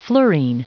Prononciation du mot fluorine en anglais (fichier audio)
Prononciation du mot : fluorine